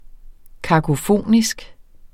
Udtale [ kɑgoˈfonisg ]